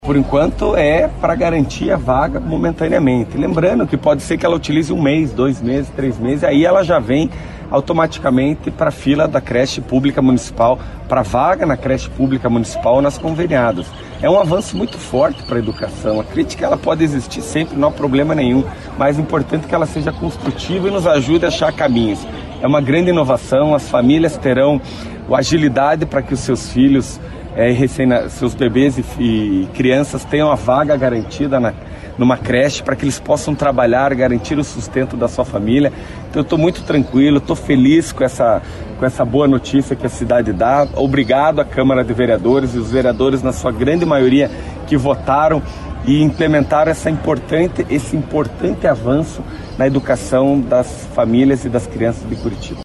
Para os parlamentares, o valor indicado como auxílio pode não ser suficiente para atender as famílias que não têm condições financeiras para pagar as creches. O prefeito falou sobre a questão.